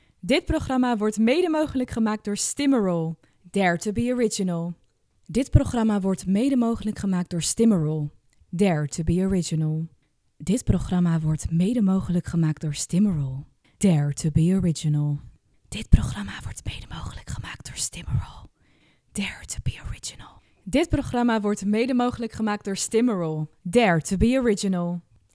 Voice over
Van activerend en motiverend tot inspirerend en rustig; zij probeert altijd met jou te zoeken naar de juiste Tone of Voice en zet graag haar acteertalent in om de radiospot/ reclame/ social content te laten stralen.